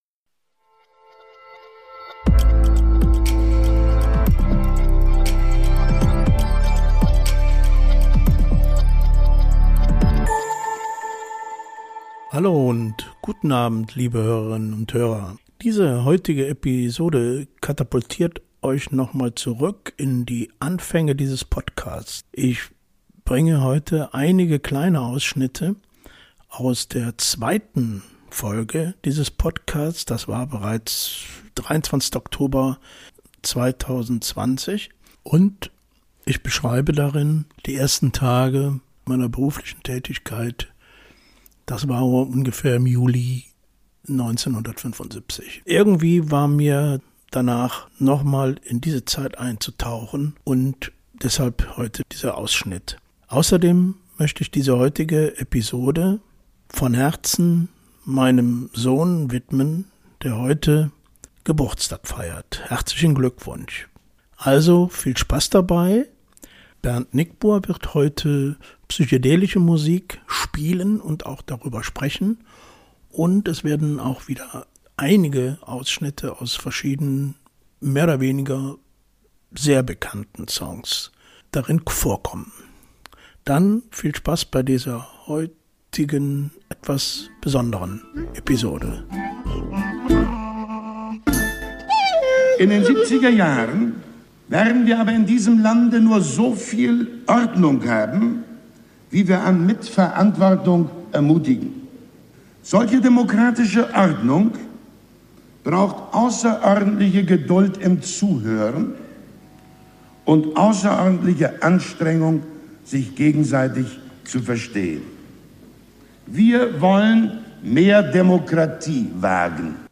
Persönliche Erinnerungen, gesellschaftliche Umbrüche und ein musikalischer Streifzug verbinden sich zu einem vielschichtigen Hörerlebnis.